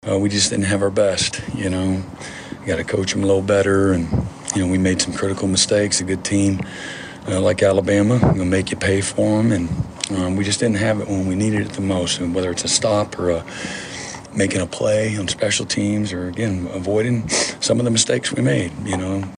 Head coach Brent Venables talked on KYFM postgame.
Venables Postgame 12-22.mp3